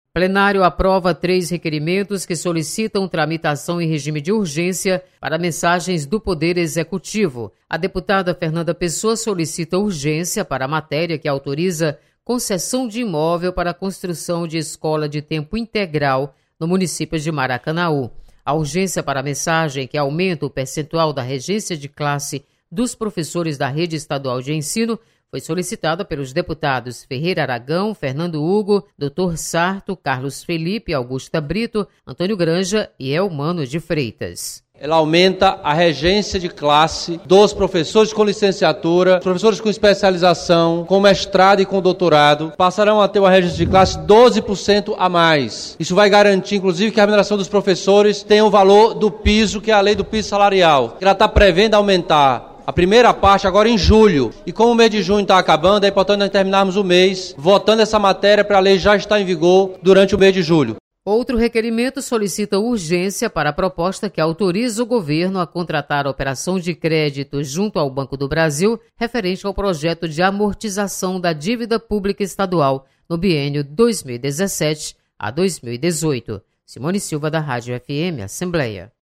Aprovada urgência para matérias do Executivo. Repórter